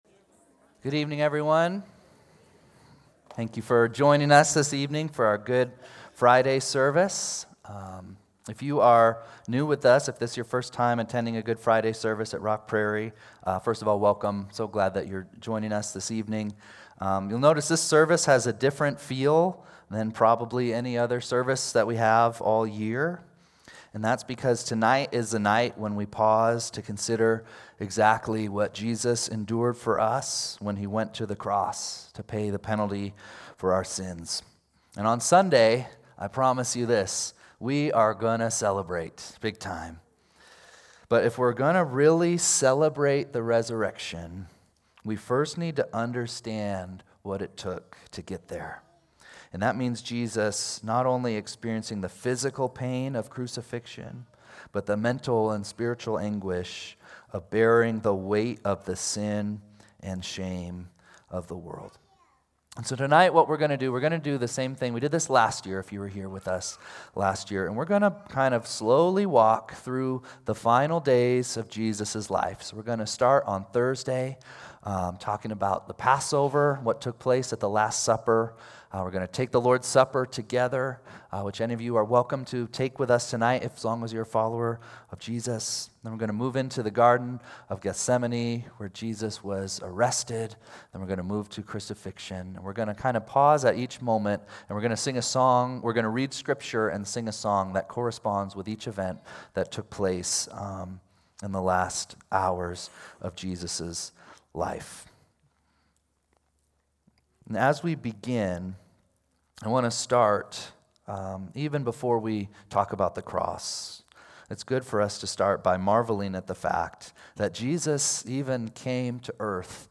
| John 1:1-12, Matthew 26:20-27, 1 Corinthians 11:23-26, Matthew 26:36-39, Matthew 27:21-30, Mark 15:33-36 Sermon Audio